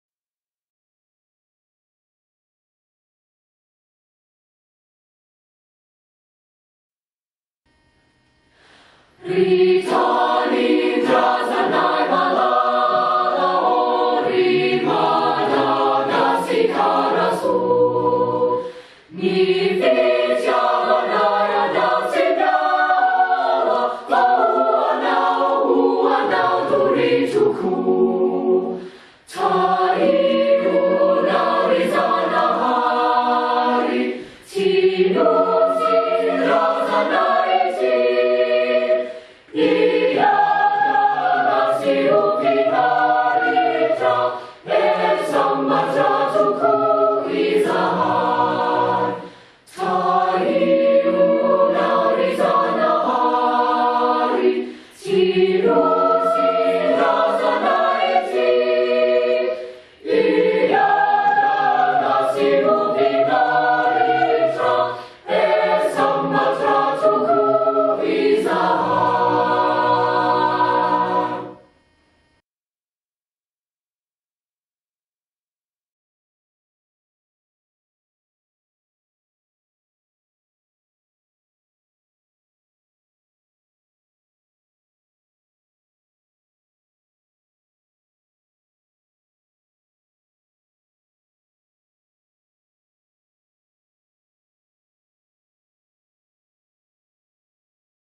National Anthem
National_Anthem_of_Madagascar_-_Ry_Tanindrazanay_malala_ô.mp3